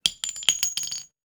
weapon_ammo_drop_13.wav